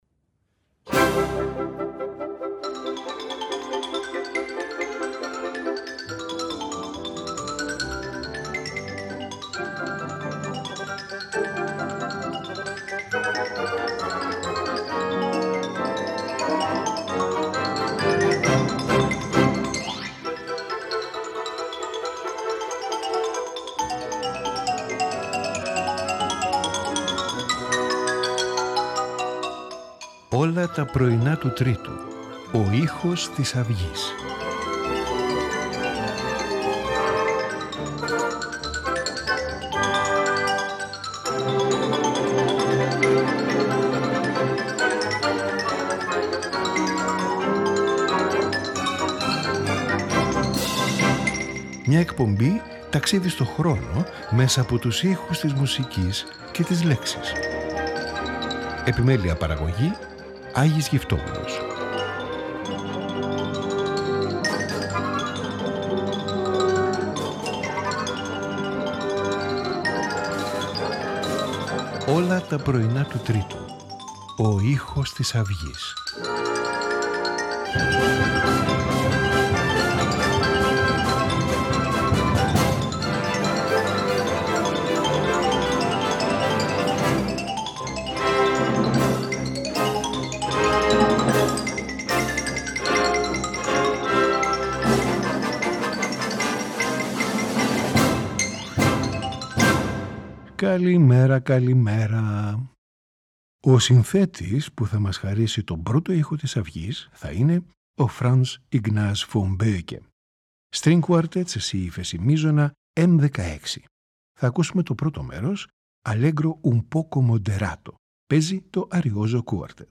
String Quartet
for 2 pianos